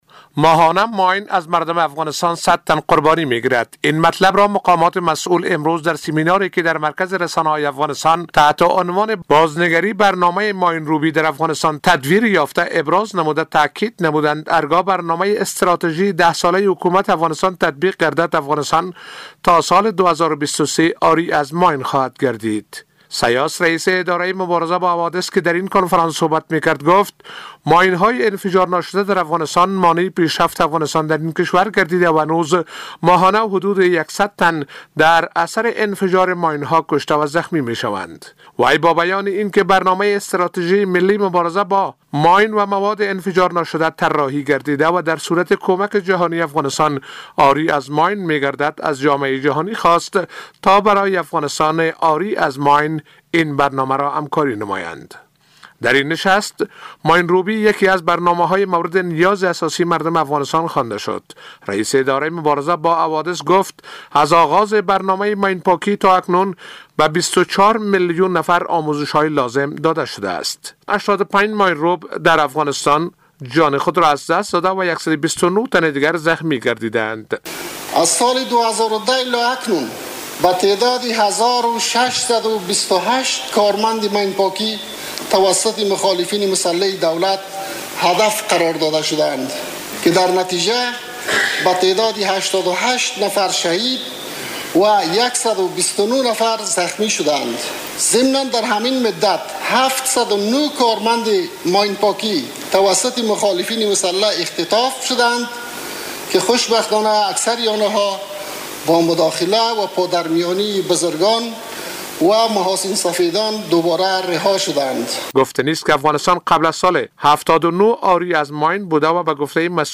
جزئیات بیشتر این خبر در گزارش